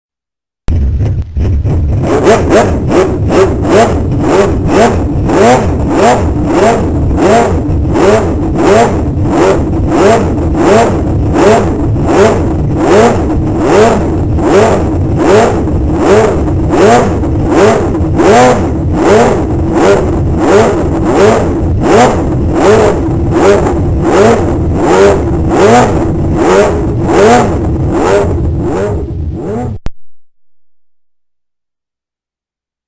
IWAYA ENGINE SOUND COLLECTION